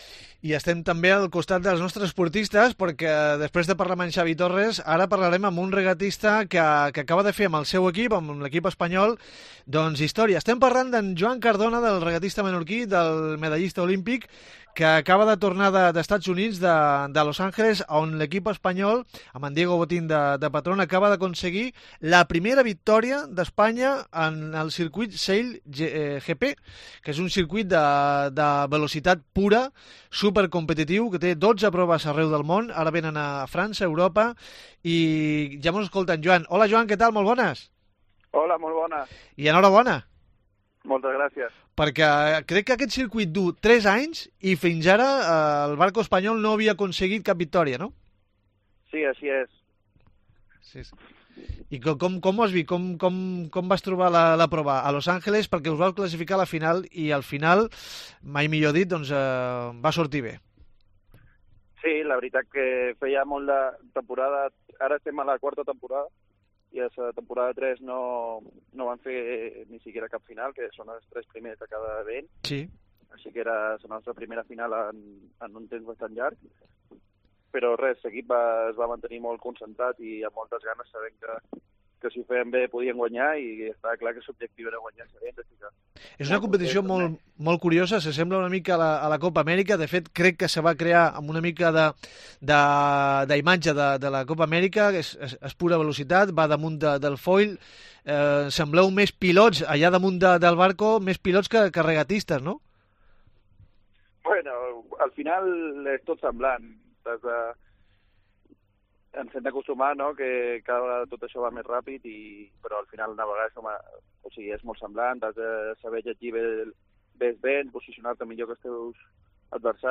El medallista olímpico menorquín nos habla de la primera victoria del barco español en el circuito Sail GP